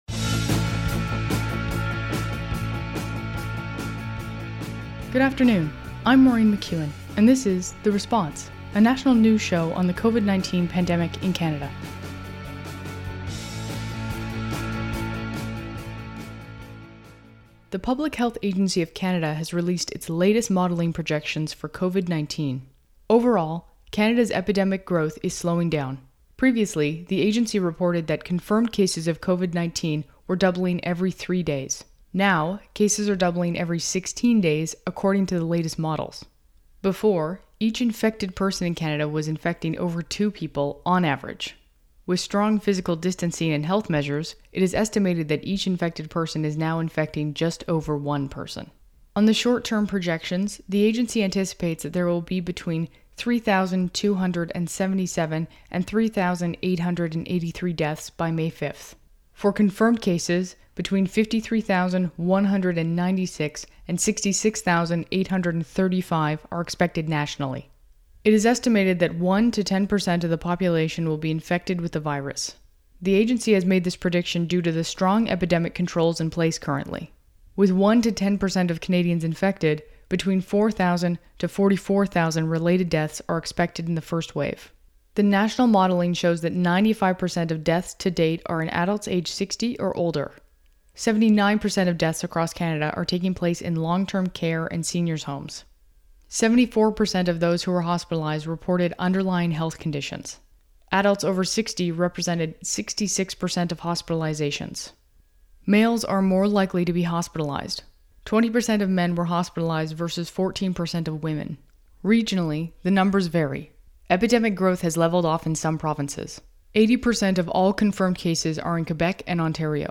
National News Show on COVID-19
Credits: Audio clips: Canadian Public Affairs Channel.
Type: News Reports
192kbps Stereo